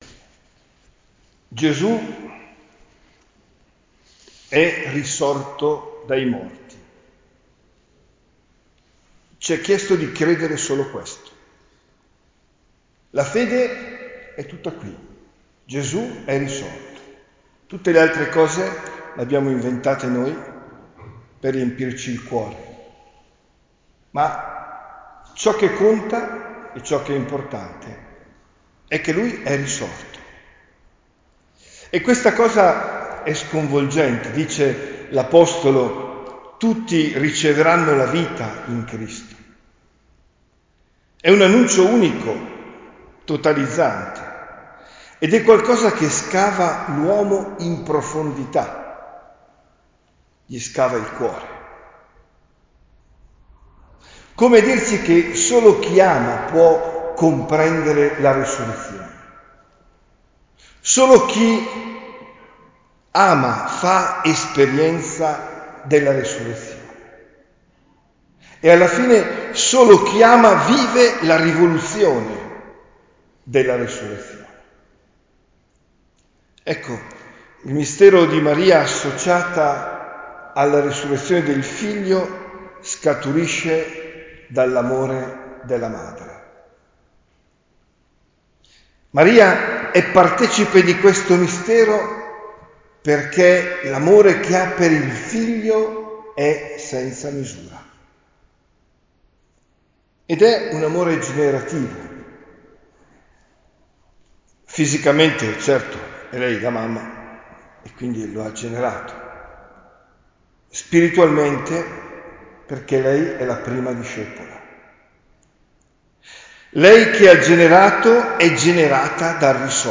OMELIA DEL 15 AGOSTO 2023